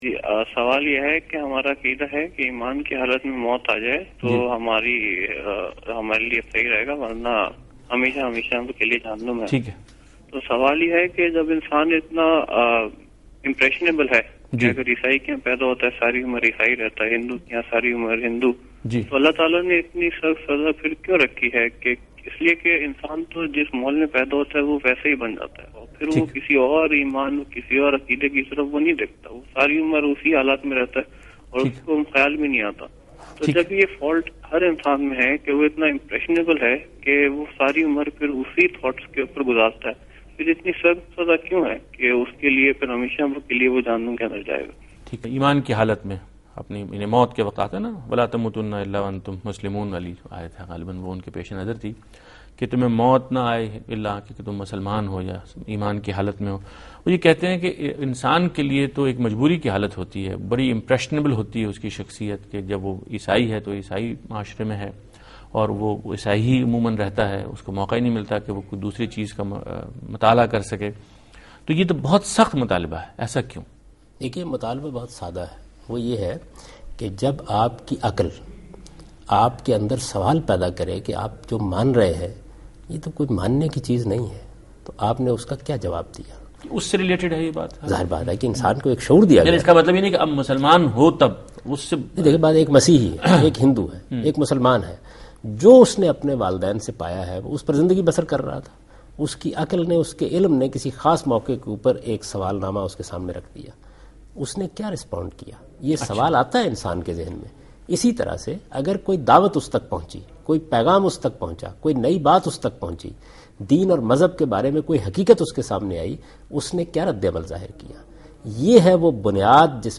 Category: TV Programs / Dunya News / Deen-o-Daanish / Questions_Answers /
Answer to a Question by Javed Ahmad Ghamidi during a talk show "Deen o Danish" on Duny News TV